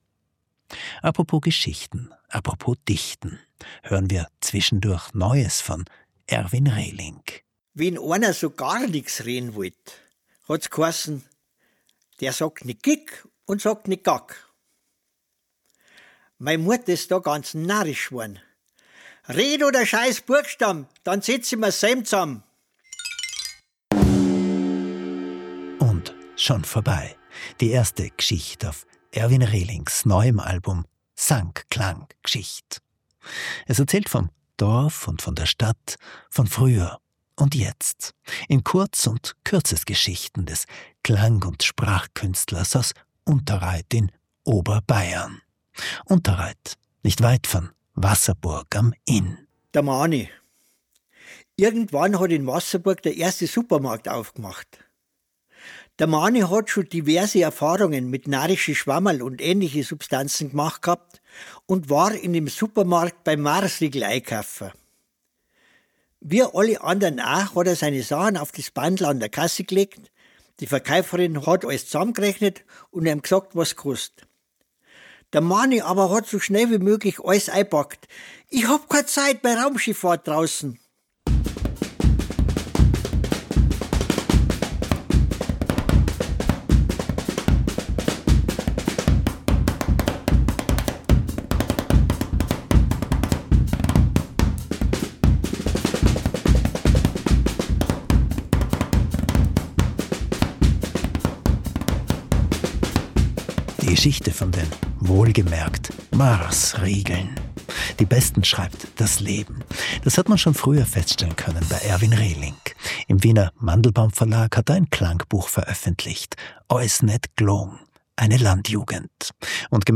Dorfgeschichten und widerspenstige Musik
Die Musik: Ein hochsensibles, zeitgenössisches Klanggeflecht – rockig, jazzig, experimentell und in direkter Verbindung zu den Geschichten.